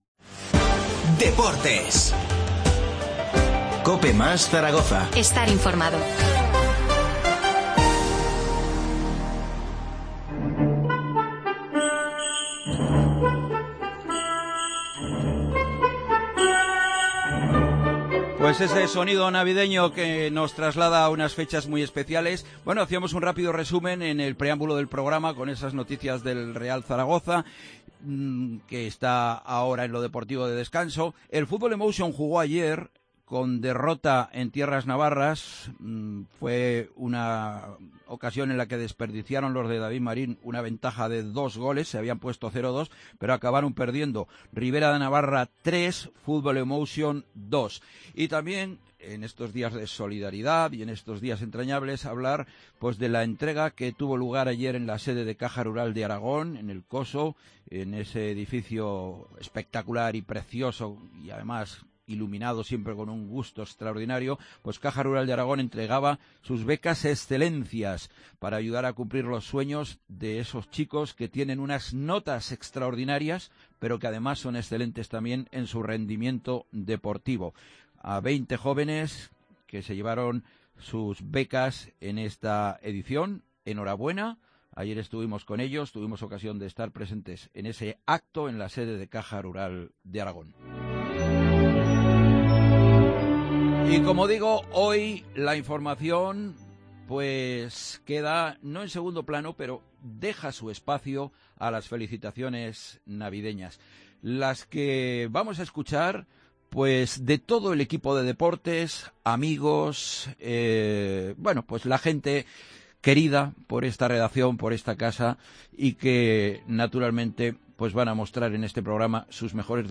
Tampoco faltó, entre villancico y villancico, la aportación musical